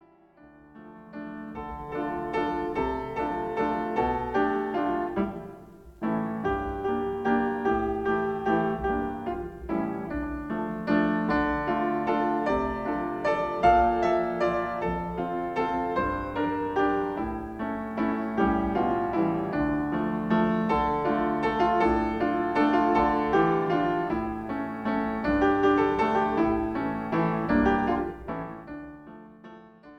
an album full of instrumental compositions and arrangements
Enjoy Jewish musical sounds from around the world.